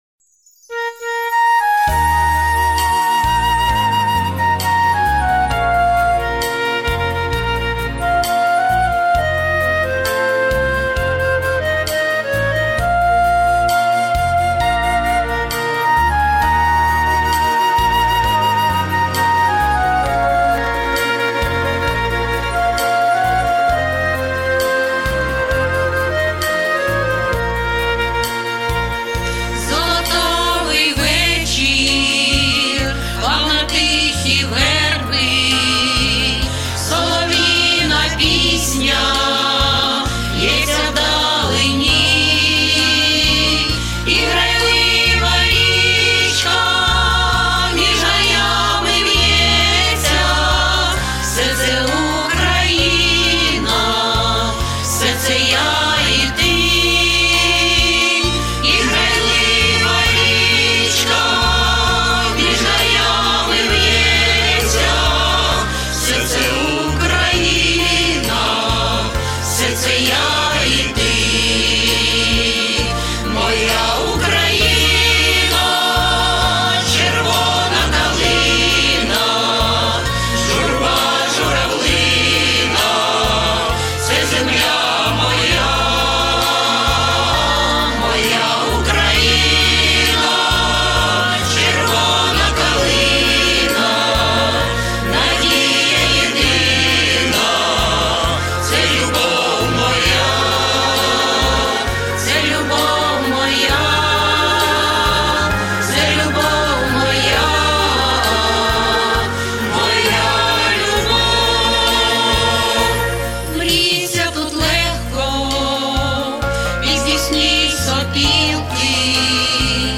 Хор Червона калина-Моя Україна.mp3